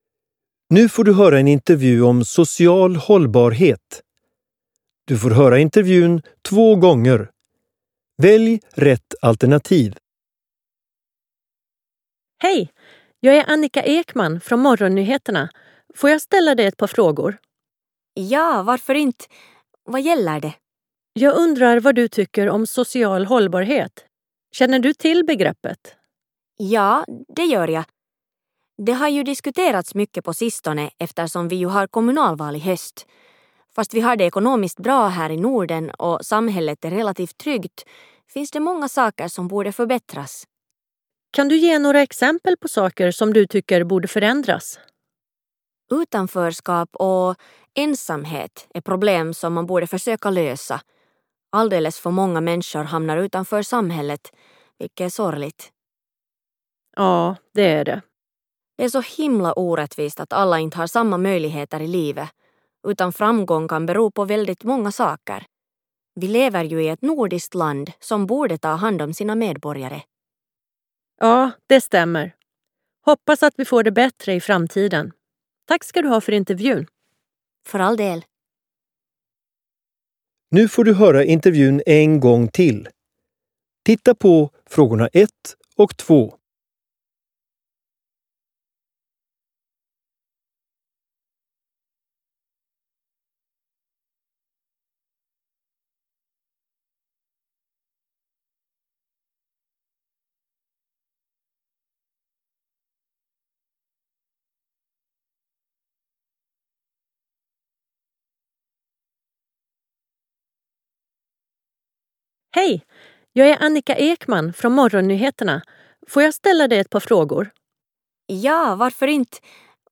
09_Hallbar_utveckling_Intervju_social_hallbarhet.mp3